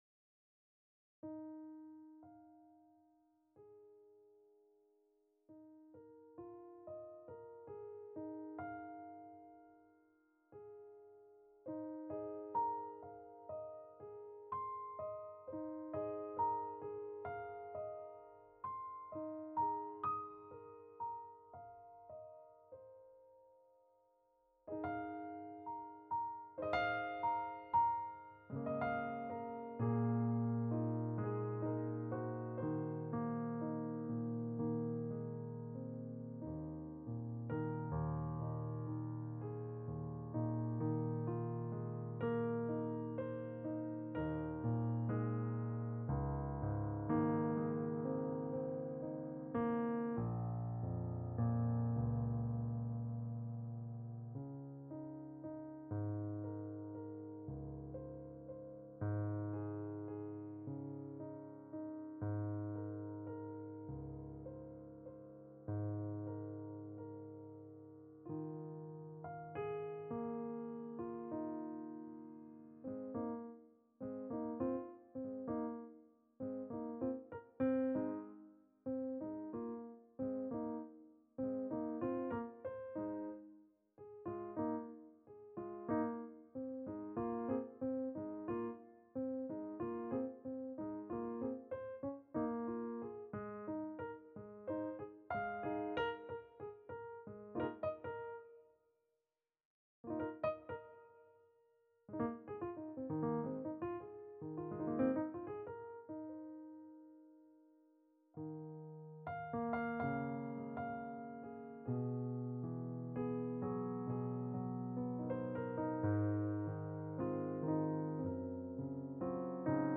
Here are two random pieces of piano audio. What happened here is I sat down and just started playing; I didn't think about the scale, or rhythym, or mood, or much for the first one, and for the second I had NIN's Ghosts VI: Together in mind.
It really is random and feels like nothing.